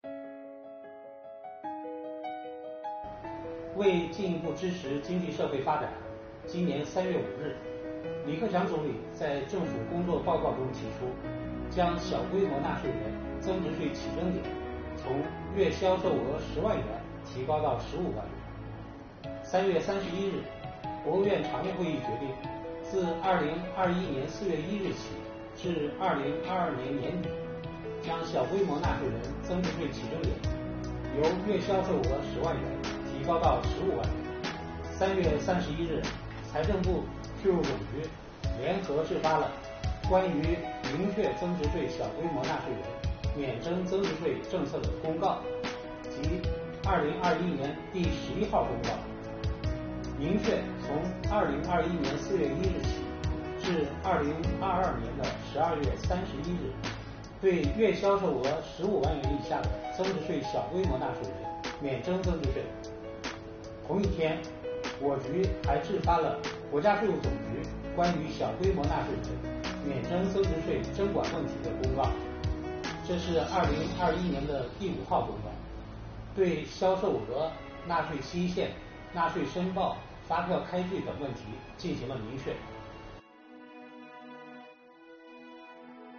为方便纳税人了解掌握、及时享受这项政策和服务举措，8月31日，国家税务总局推出“税务讲堂”网上公开课，税务总局货物和劳务税司副司长吴晓强围绕销售额、纳税期限、纳税申报和发票开具等问题，详细解读了小规模纳税人免征增值税政策。